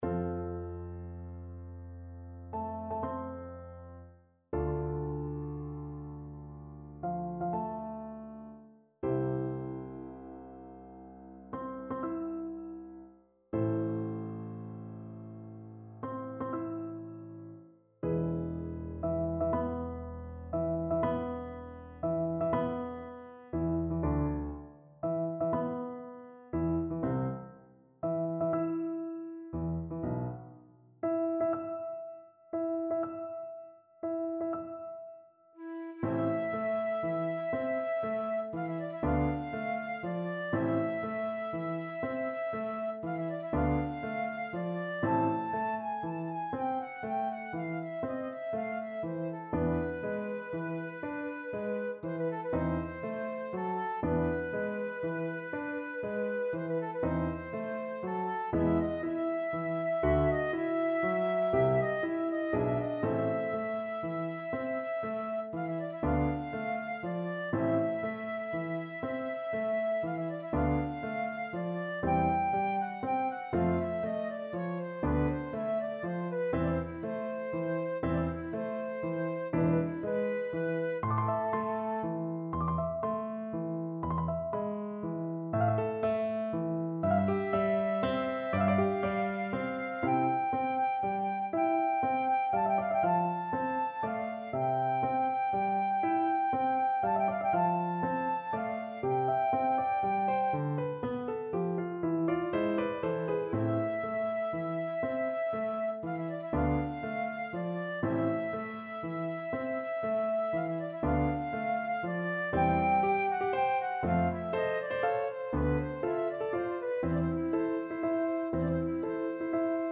Flute version
9/8 (View more 9/8 Music)
Classical (View more Classical Flute Music)